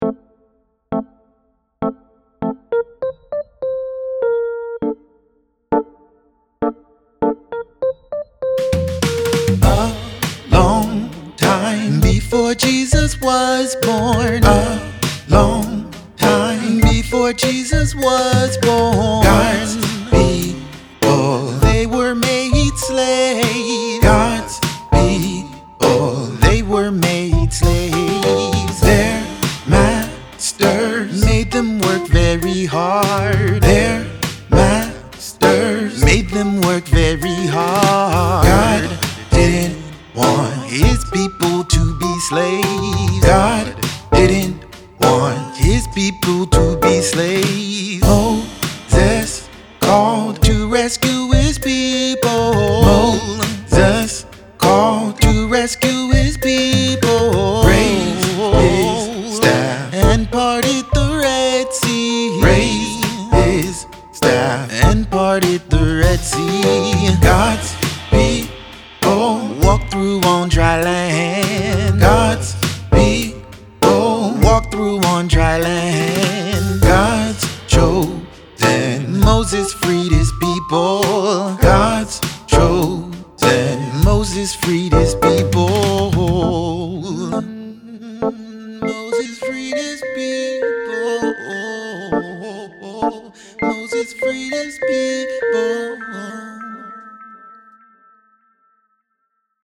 Chords: F Gm Am Gm